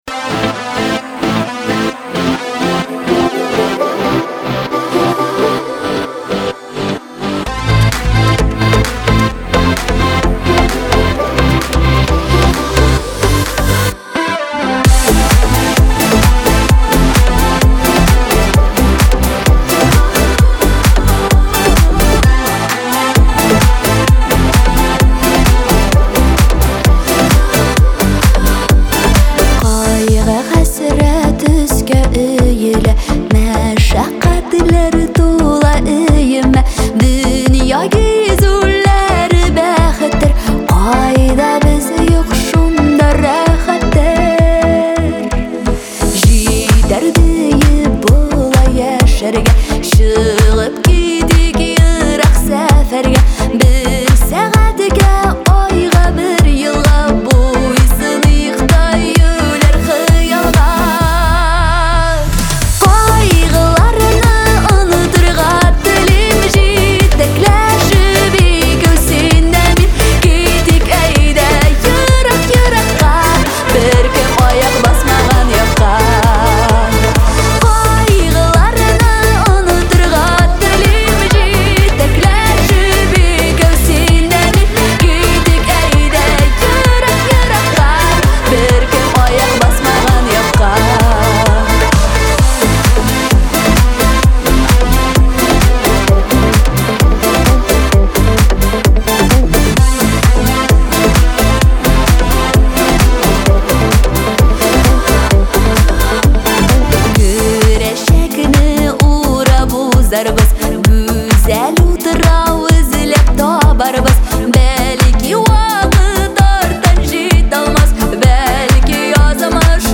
Качество: 320 kbps, stereo
Казахская музыка